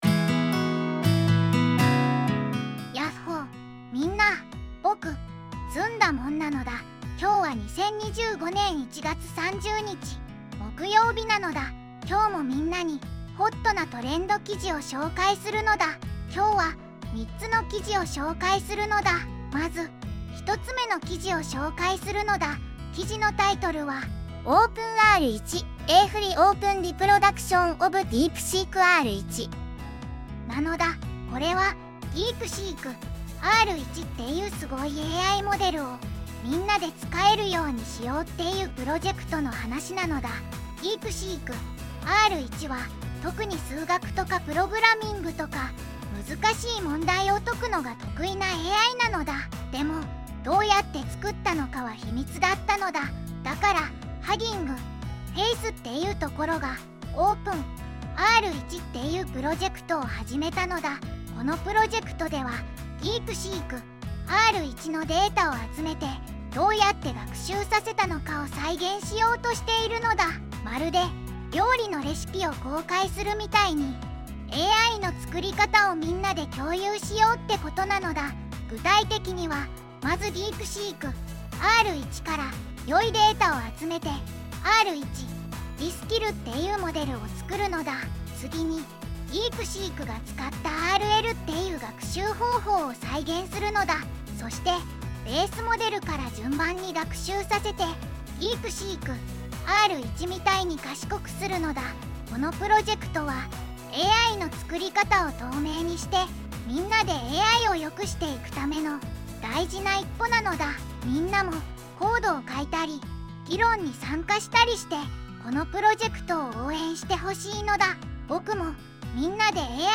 ずんだもん